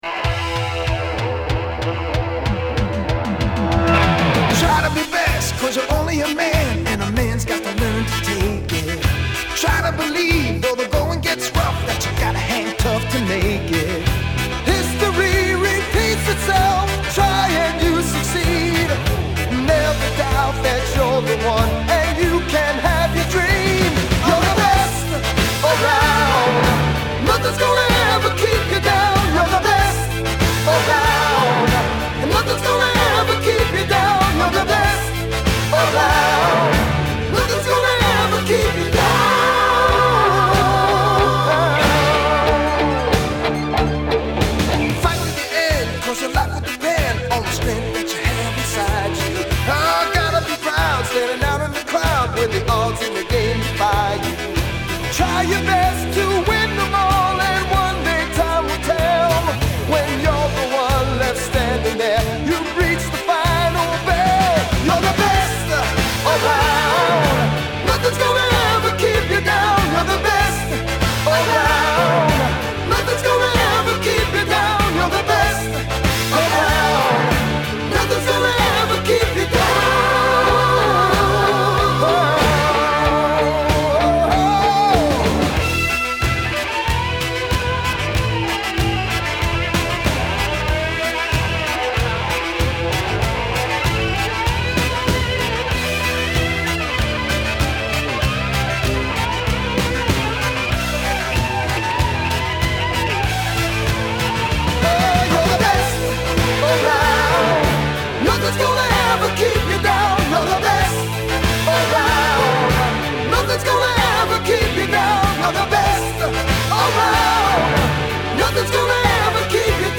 I ripped it from my original vinyl copy this very morning.